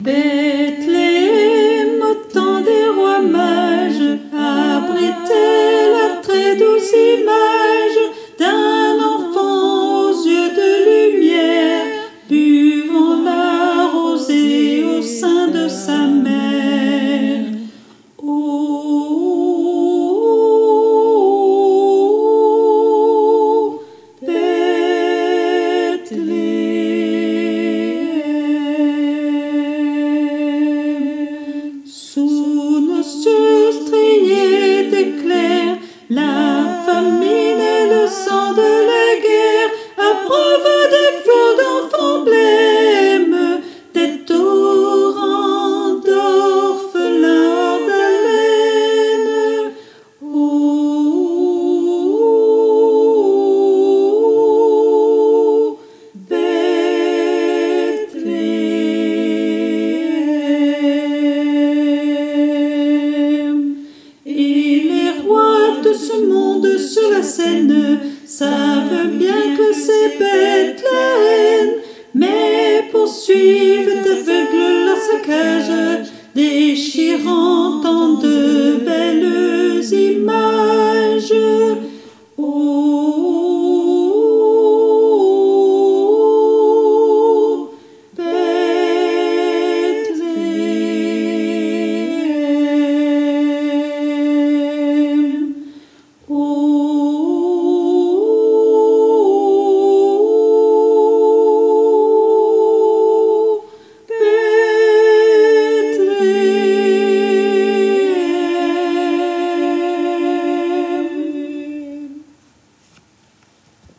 Mp3 versions chantées
Soprano Et Autres Voix En Arriere Plan